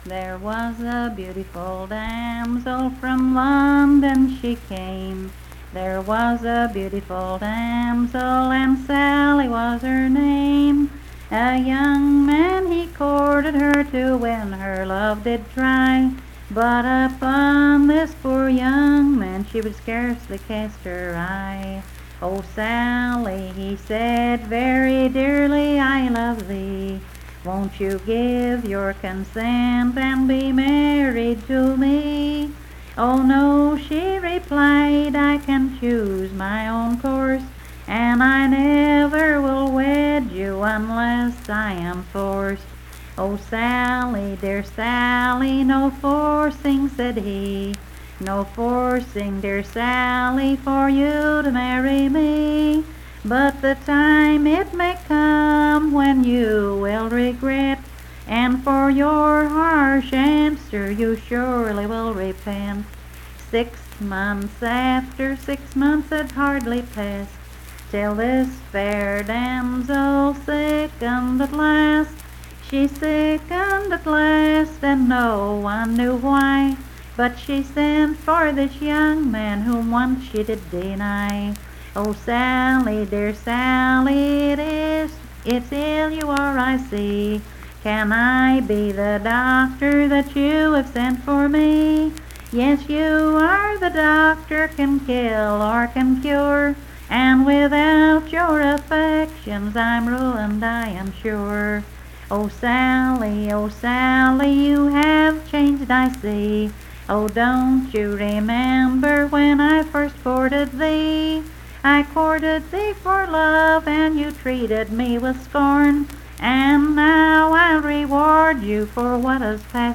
Unaccompanied vocal music
Verse-refrain 8d(4).
Performed in Coalfax, Marion County, WV.
Voice (sung)